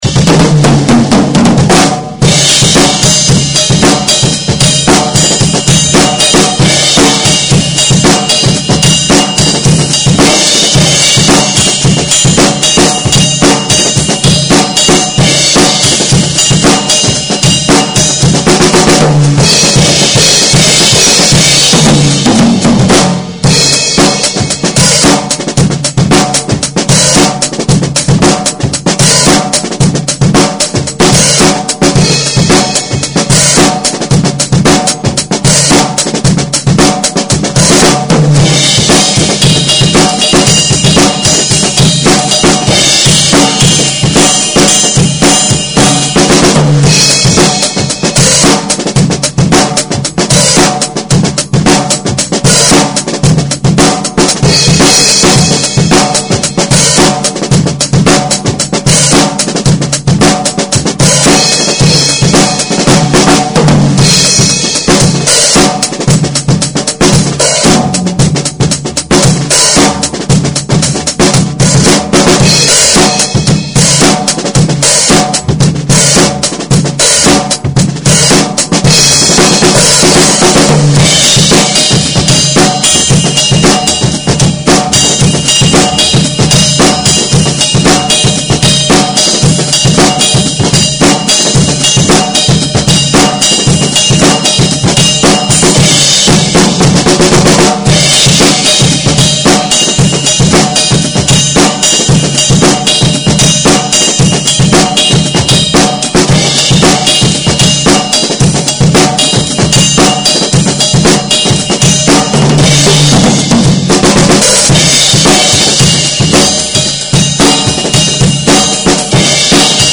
(drum only)
으로 걍 제가 연습실에서 혼자 연습한 거 올려봅니당 엠피로 녹음한거라 음질은 역시나 열악합니다.